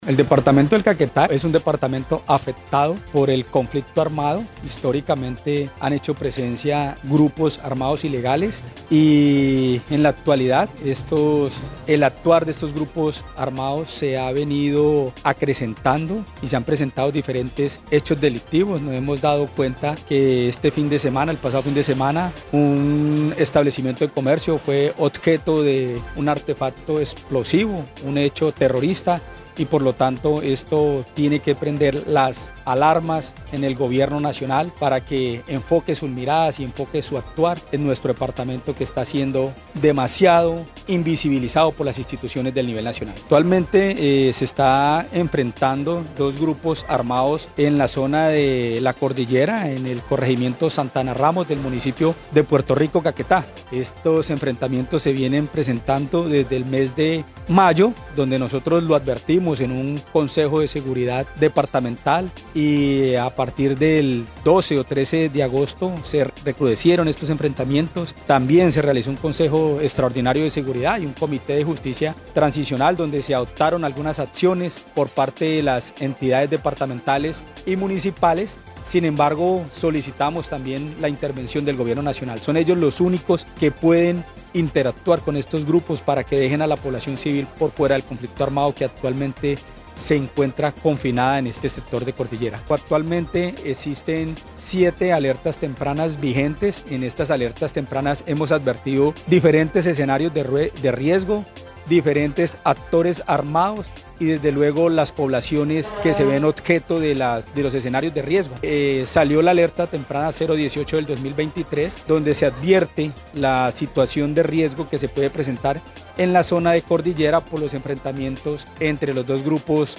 Edwin Leal, Defensor del Pueblo en Caquetá, dijo que, lo sucedido en esta parte del país, donde hay confinamiento en zona rural de Puerto Rico por enfrentamientos entre disidencias de las Farc, es invisibilizado desde el nivel central.